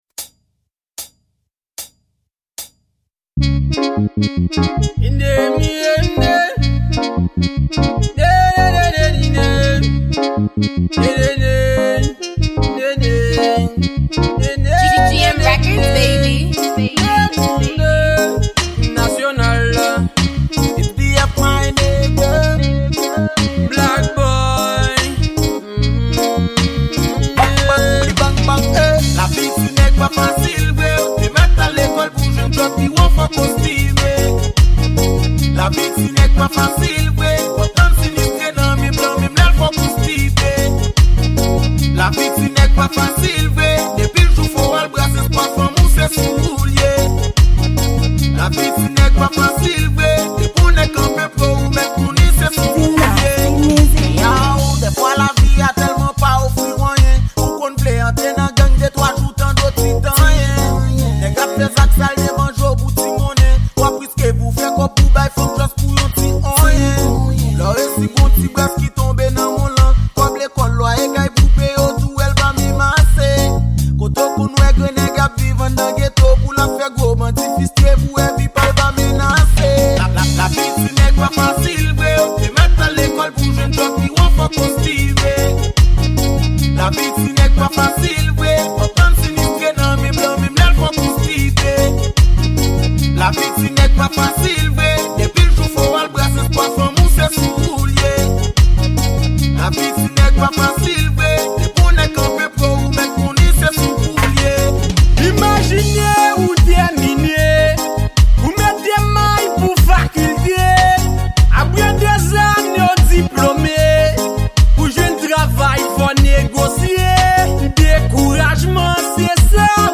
Genre: Reggae